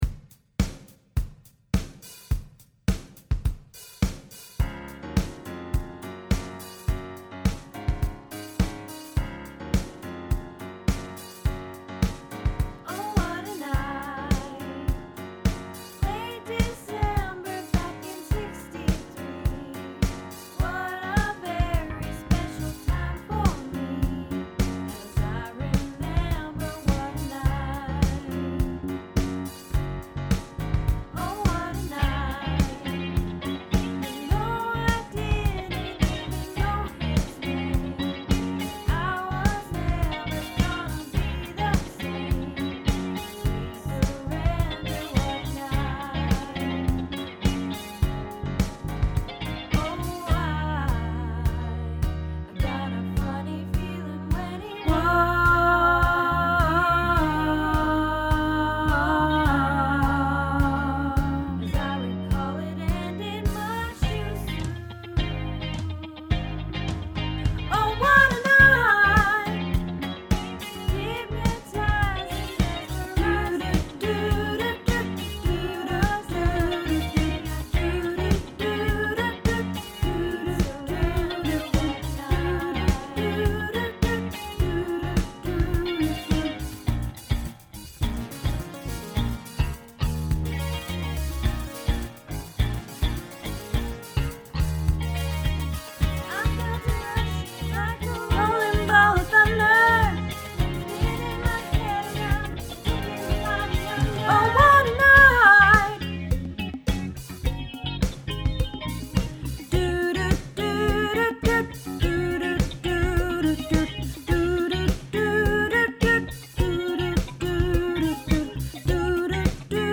Oh What A Night - Tenor